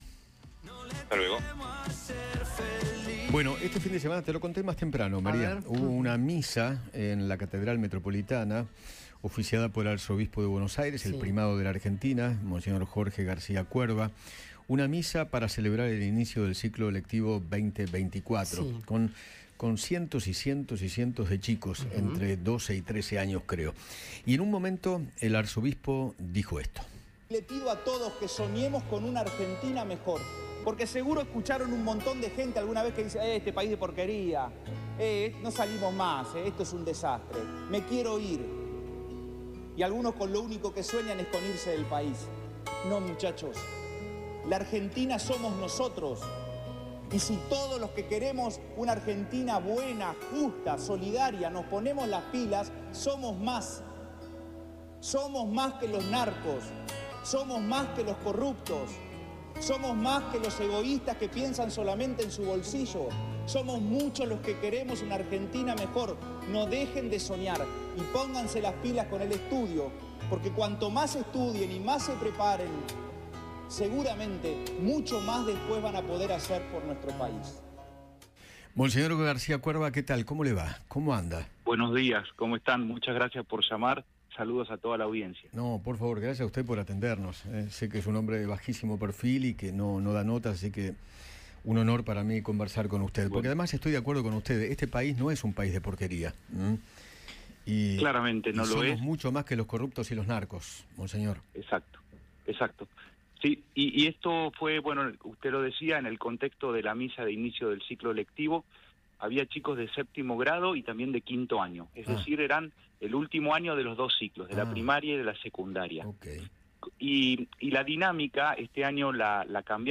Jorge Ignacio García Cuerva, arzobispo de Buenos Aires, habló con Eduardo Feinmann sobre la situación en Rosario y se refirió a la crisis económica que sufren los más necesitados en Argentina.